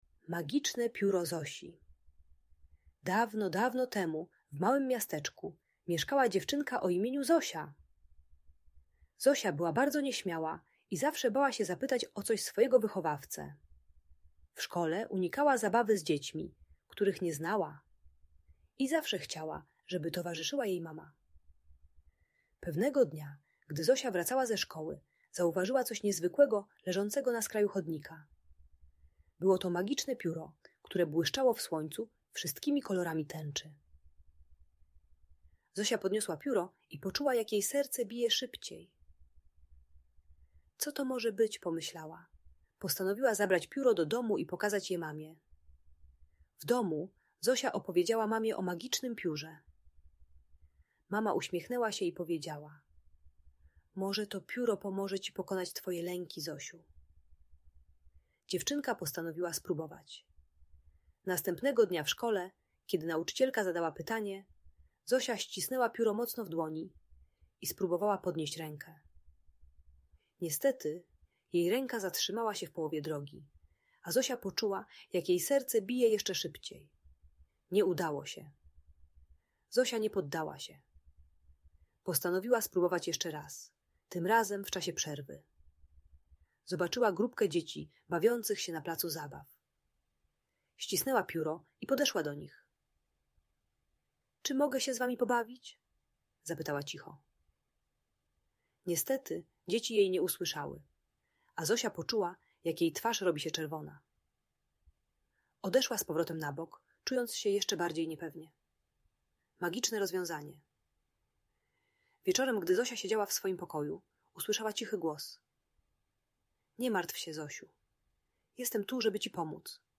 Magiczne Pióro Zosi - Bajkowa Historia - Audiobajka